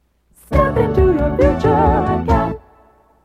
Promotional Audio/Radio Jingle
audio cassette